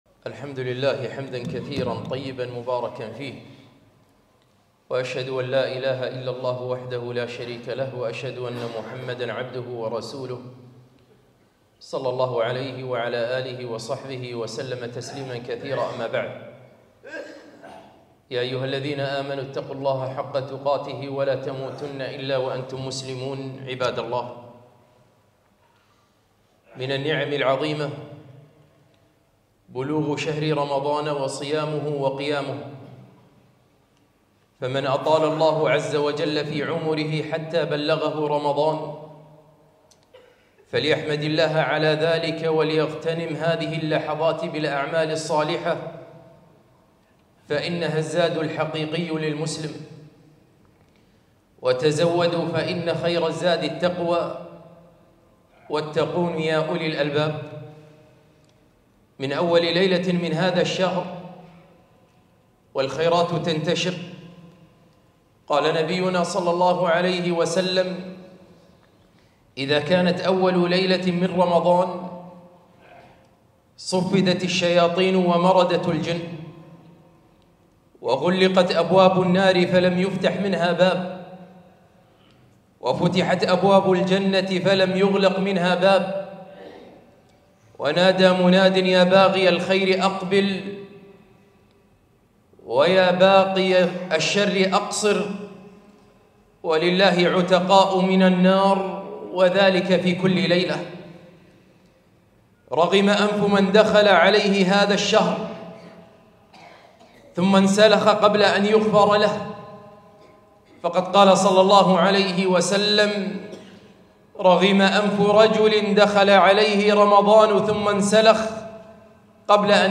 خطبة - شهر الصيام